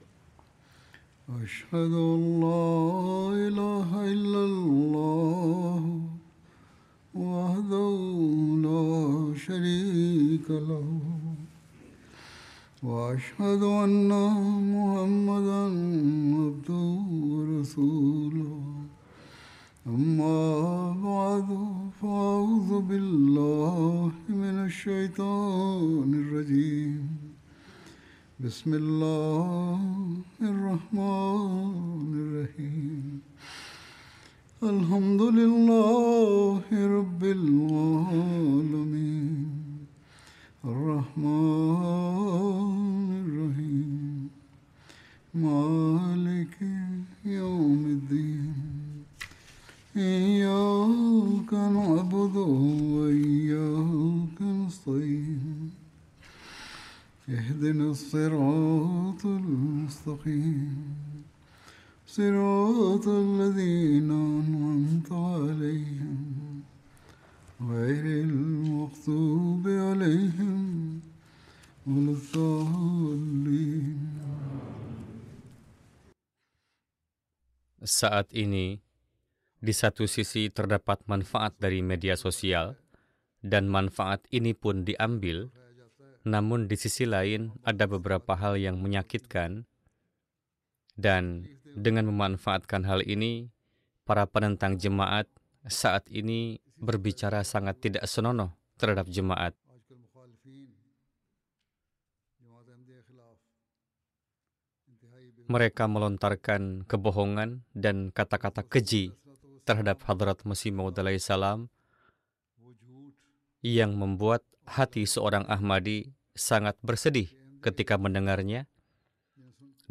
Indonesian Friday Sermon by Head of Ahmadiyya Muslim Community